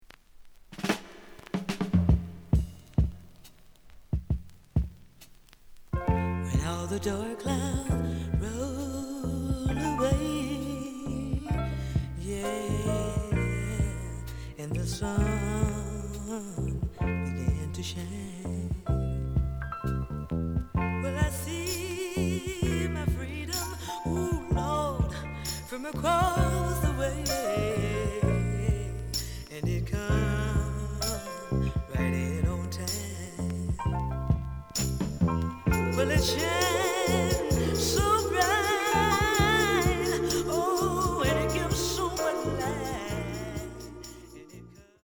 The listen sample is recorded from the actual item.
●Genre: Soul, 70's Soul